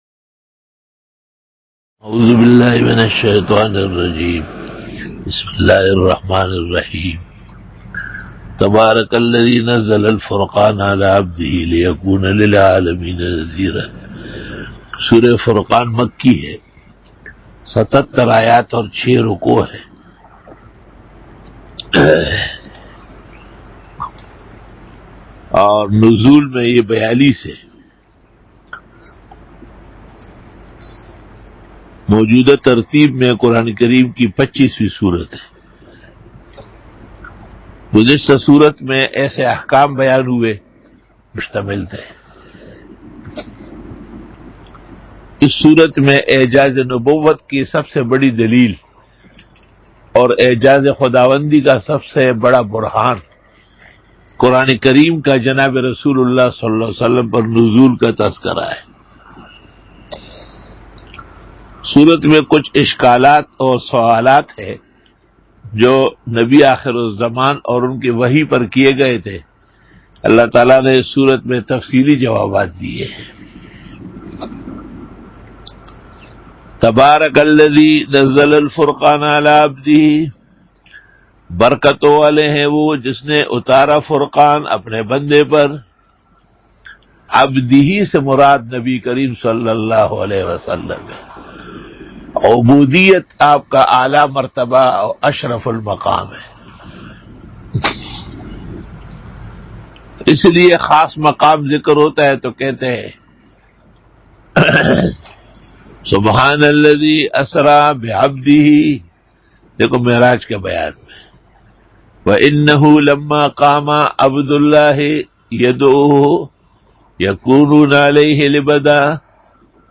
Dora-e-Tafseer 2020 Bayan